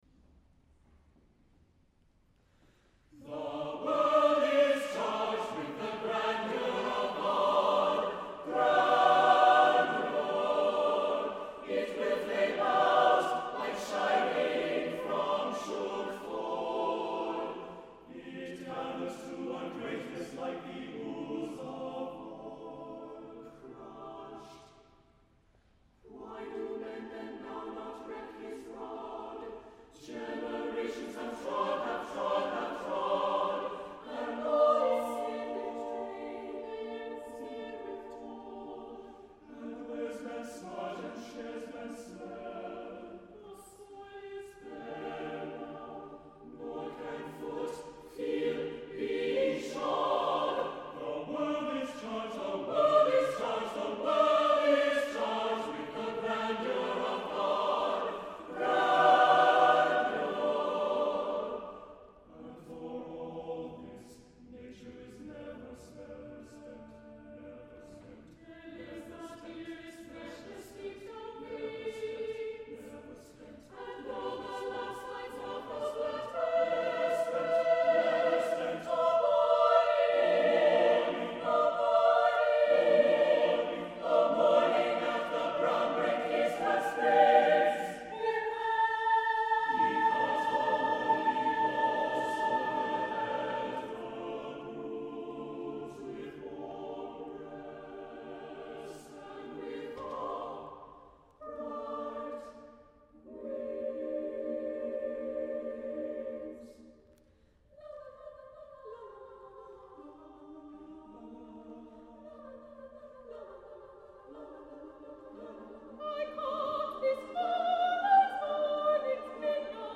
for SATB Chorus (2002)
The rhythms are fluid.
As in the opening song, this is triumphant music.